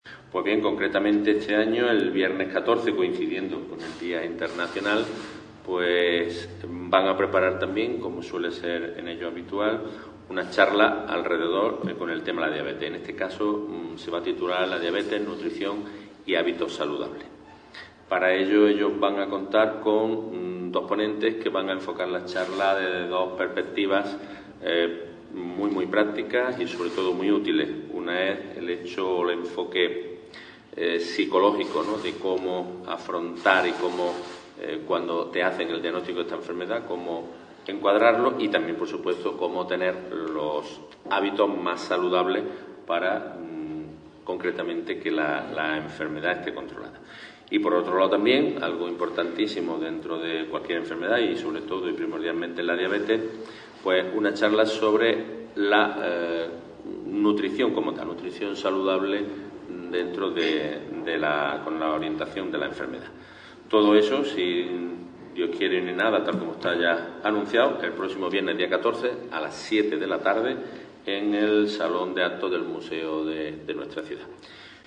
Cortes de voz J. Luque 513.86 kb Formato: mp3